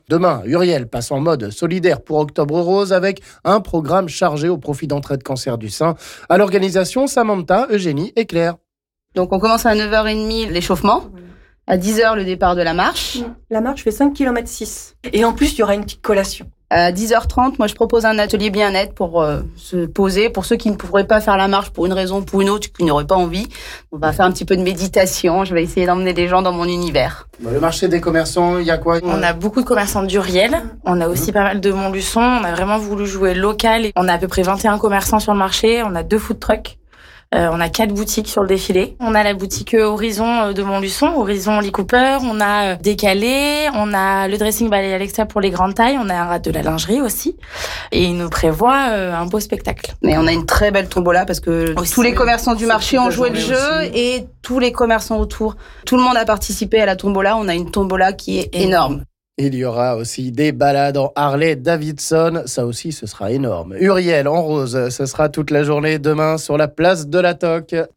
Les organisatrices de l'événement nous en disent plus ici...